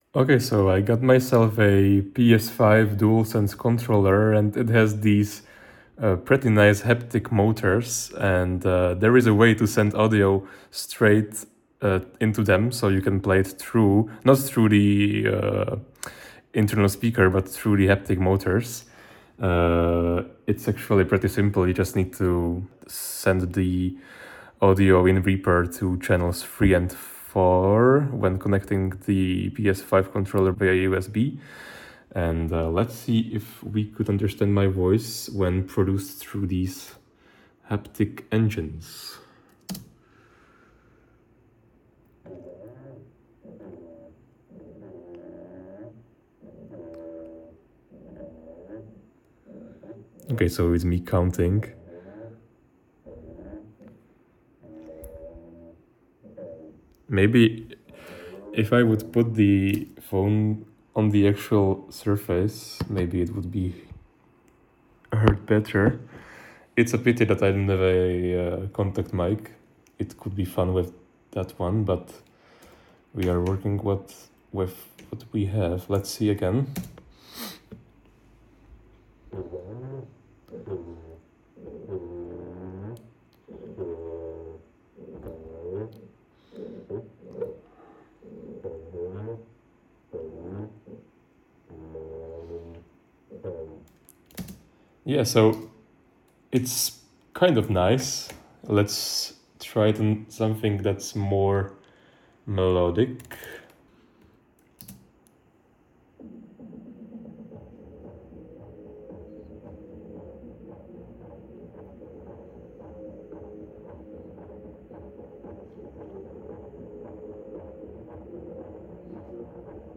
I got myself a new PS5 controller and instead of doing the usual stuff that should be done with these, I decided to find out how to play audio through the enhanced haptic motors instead. Turns out it's not difficult at all, and it works surprisingly well. If you are bored and have nothing to do, I've attached a short recording where I explain my setup and demonstrate how the motors reproduce different types of audio files.